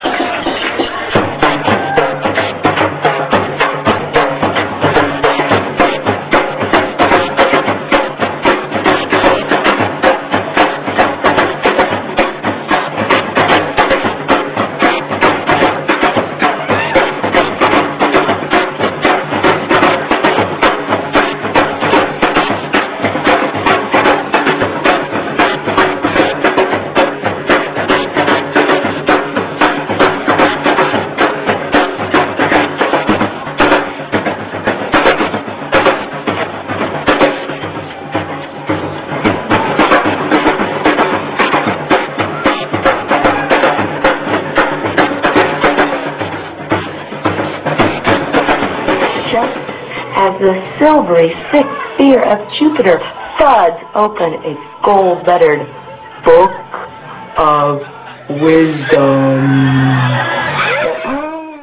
spoken word/trapeze/dance/video performance
at Mother's Nine Warehouse in Oakland &
at the center cafe in Black Rock City